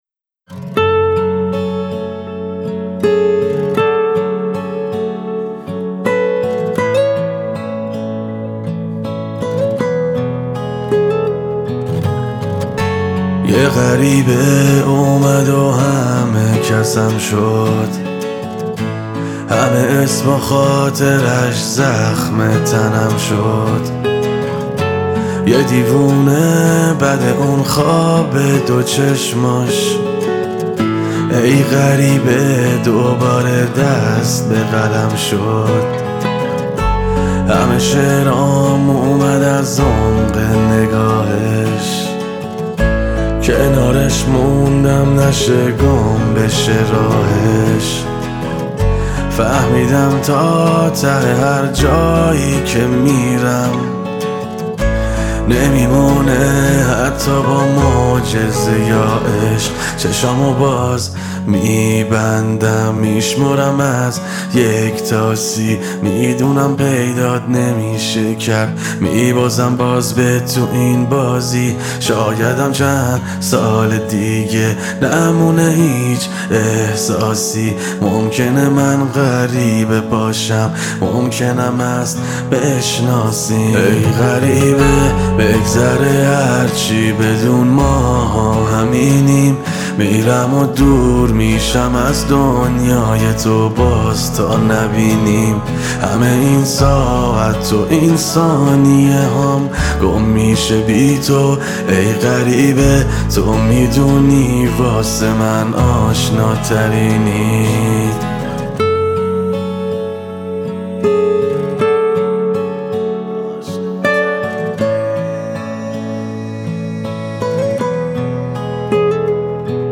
آهنگی احساسی و دلنشین